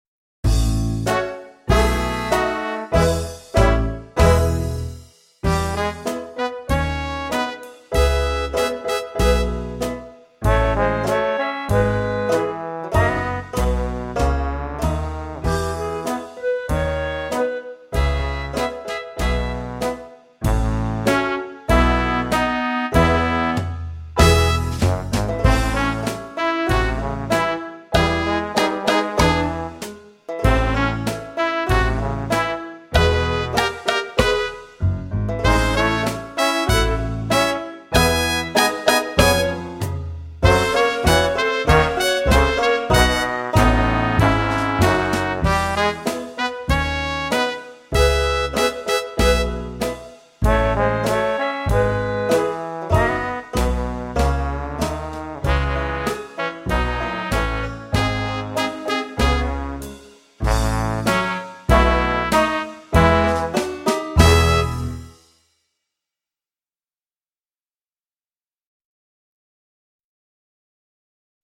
VS Woodpecker Tap (backing track)